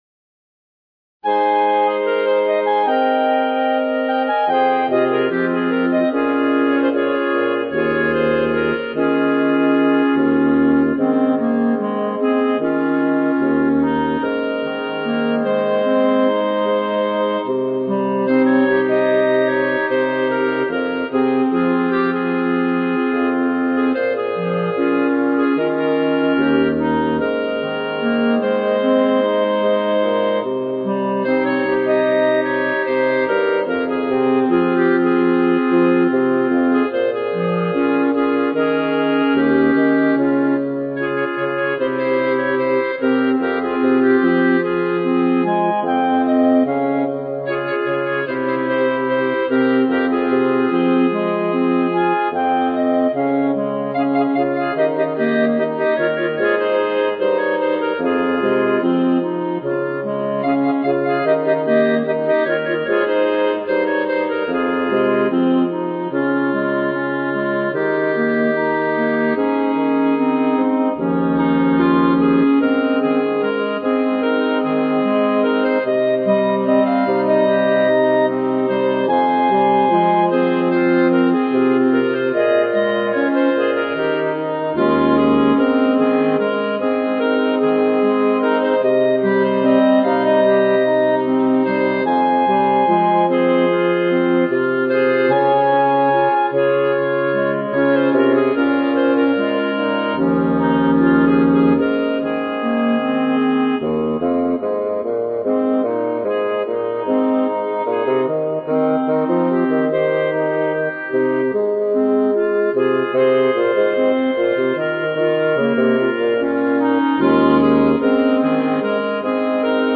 B♭ Clarinet 1 B♭ Clarinet 2 B♭ Clarinet 3 Bass Clarinet
单簧管四重奏
风格： 流行